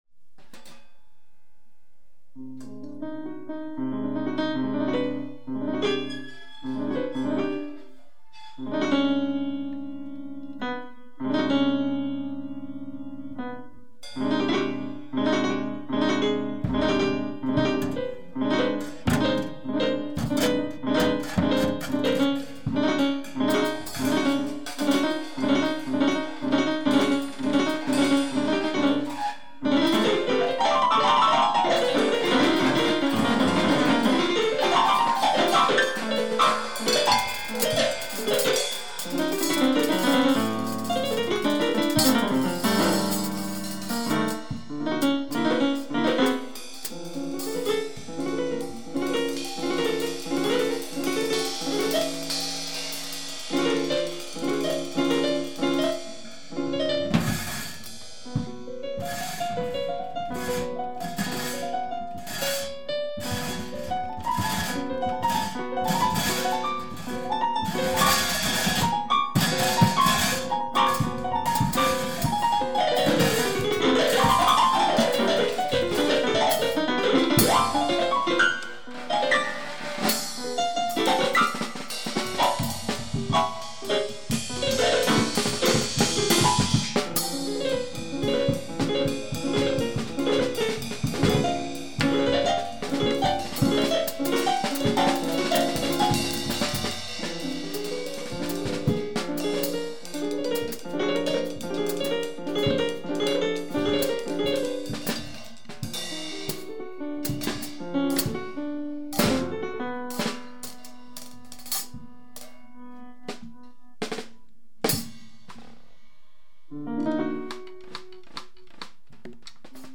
(drums)
(bass)